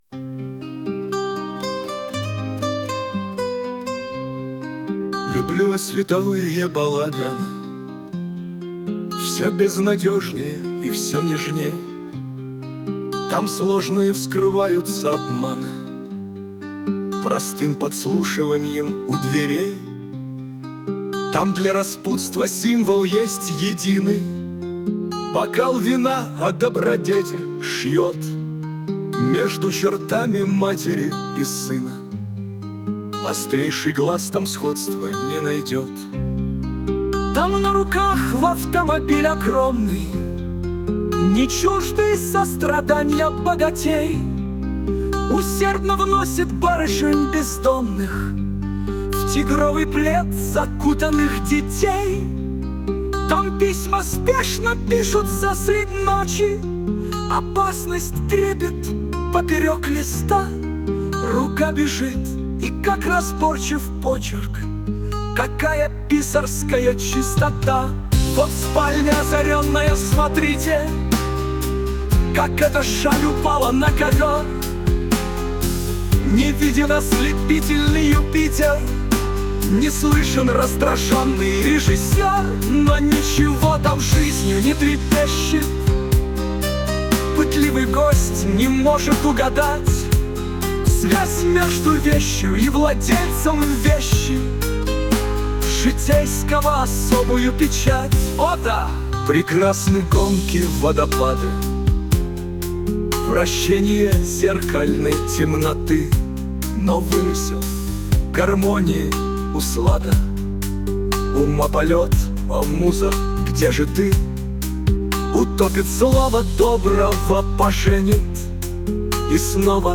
КИНЕМАТОГРАФ, AI рисует и поет